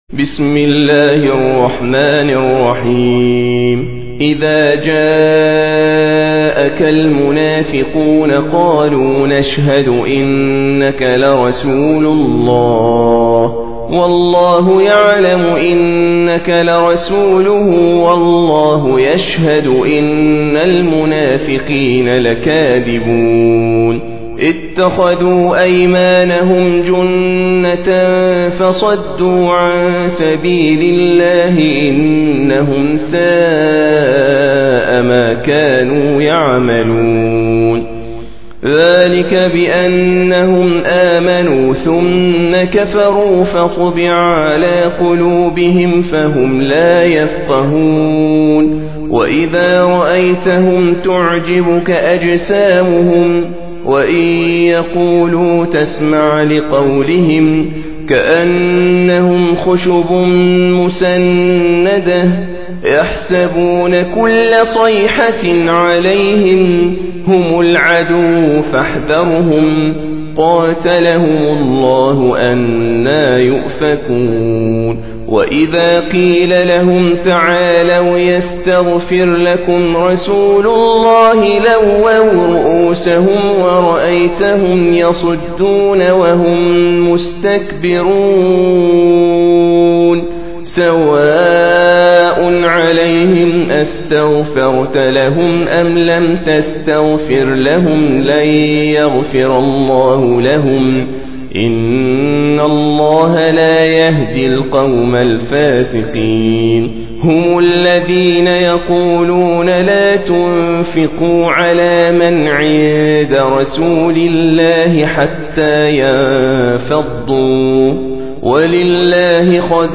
Surah Sequence تتابع السورة Download Surah حمّل السورة Reciting Murattalah Audio for 63. Surah Al-Munafiq�n سورة المنافقون N.B *Surah Includes Al-Basmalah Reciters Sequents تتابع التلاوات Reciters Repeats تكرار التلاوات